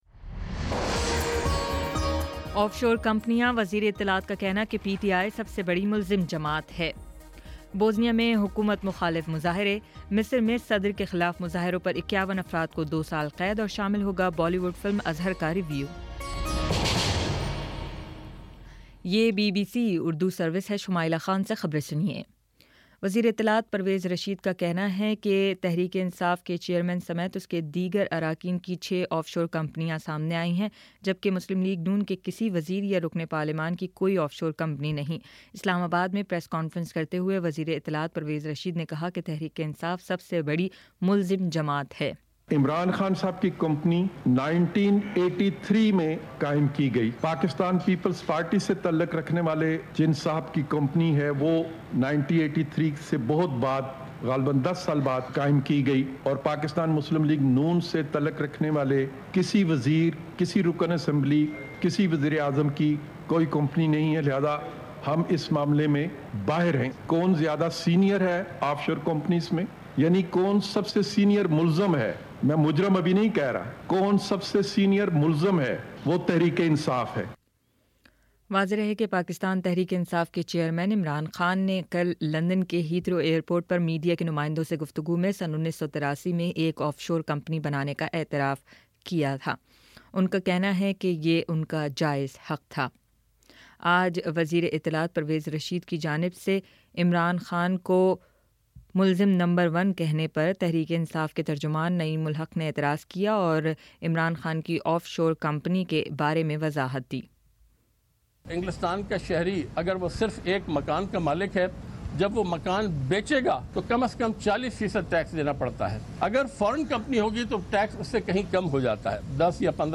مئی 14: شام سات بجے کا نیوز بُلیٹن